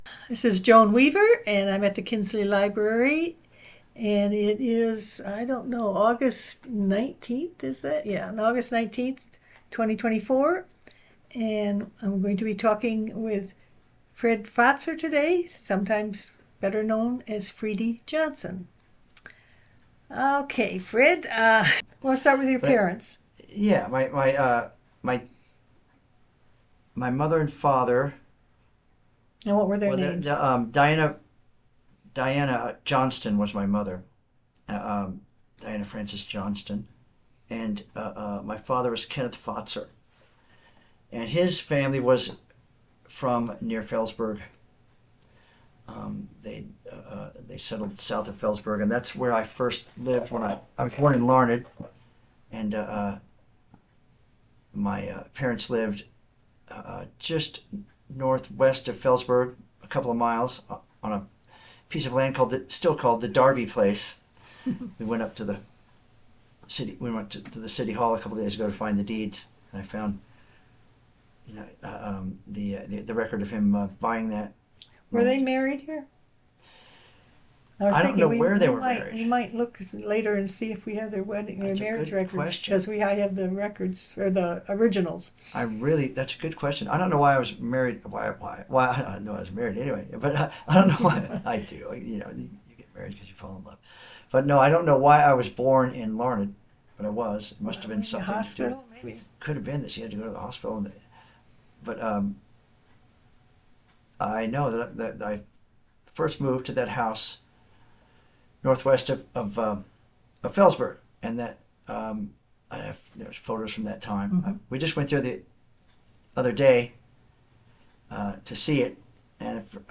Freedy Johnston Comes Home An Interview with Fred Fatzer August 26, 2024 Kinsley Library Audio Recording Transcript Video Clip